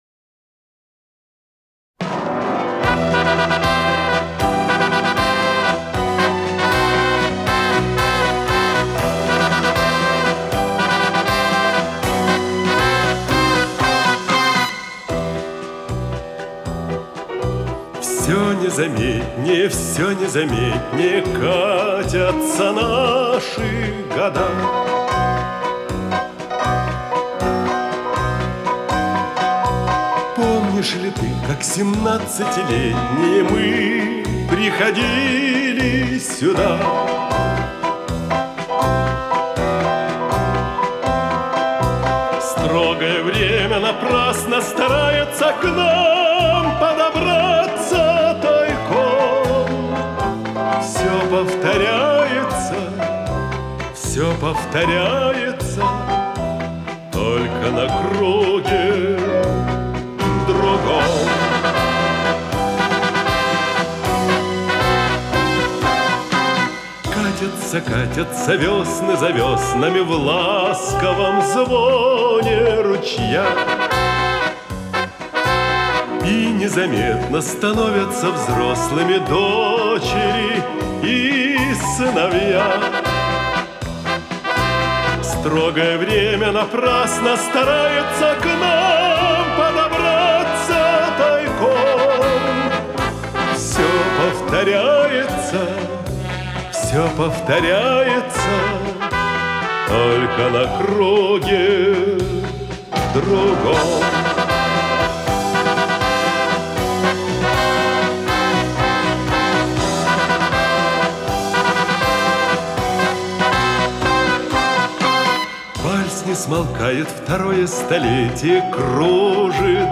с профессиональной магнитной ленты
ВариантДубль стерео